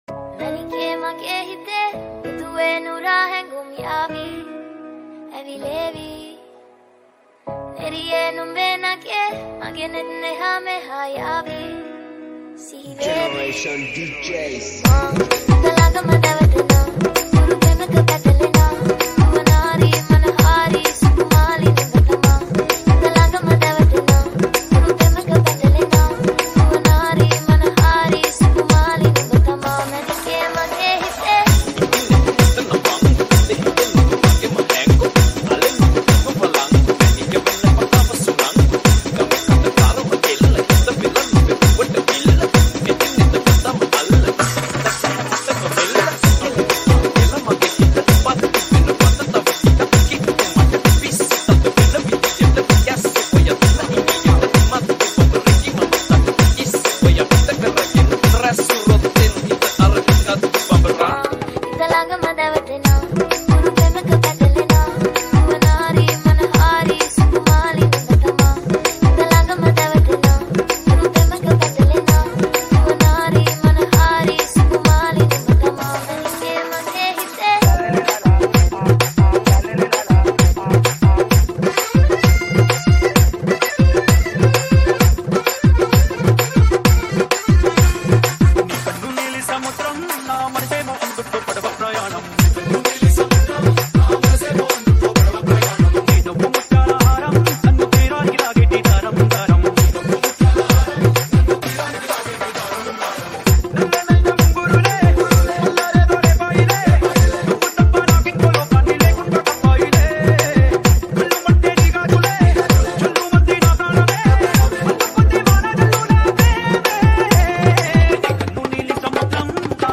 2025 New Sinhala DJ Nonstop Kawadi n Dholki